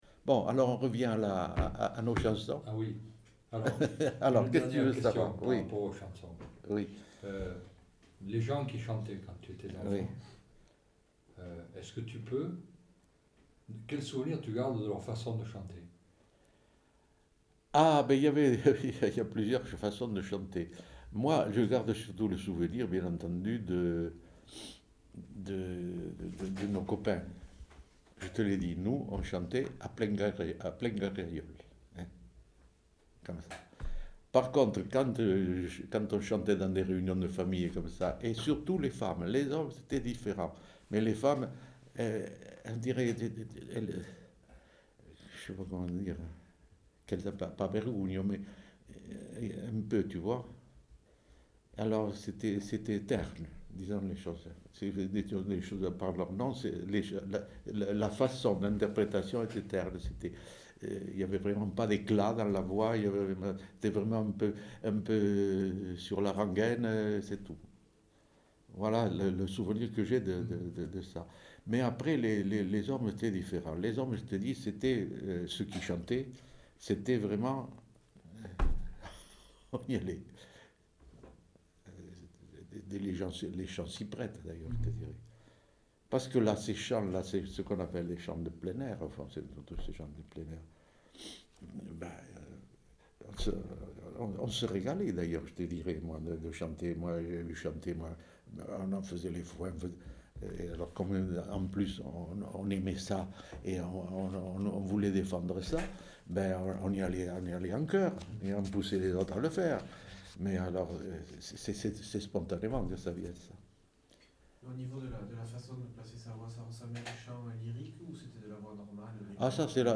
Lieu : Saint-Sauveur
Genre : témoignage thématique